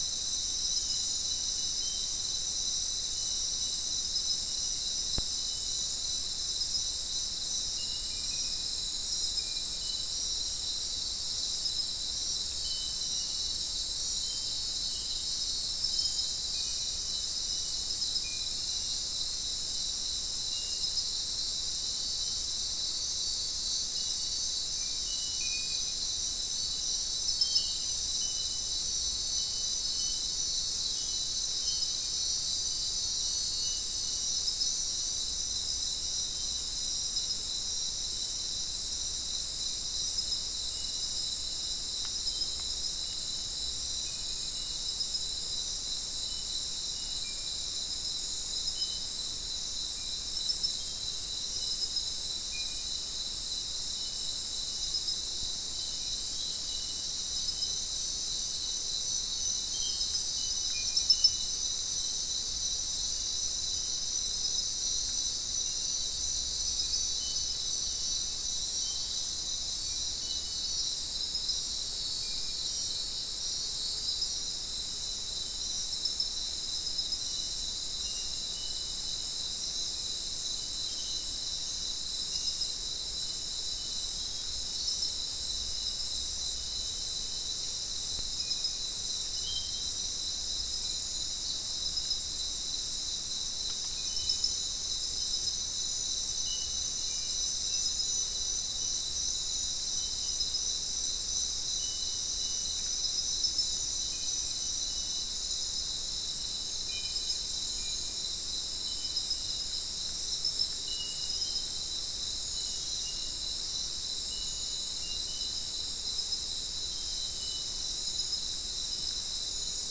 Non-specimen recording: Soundscape Recording Location: South America: Guyana: Turtle Mountain: 1
Recorder: SM3